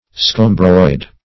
Scombroid \Scom"broid\ (sk[o^]m"broid), a. [Scomber + -oid.]